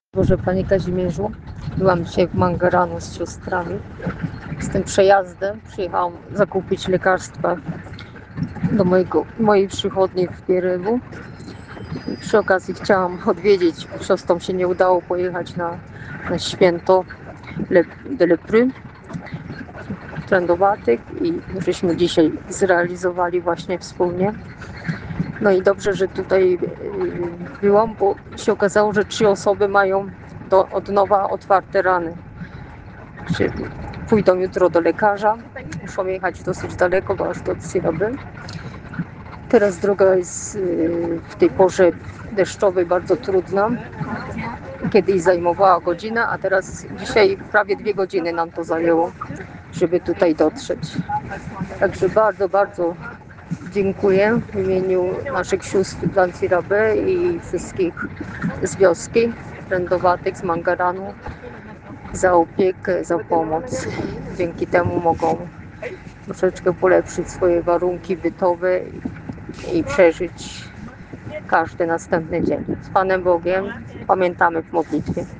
w przesłanym przez Whatsapa nagraniu serdecznie dziękuje wszystkim naszym ofiarodawcom za okazaną pomoc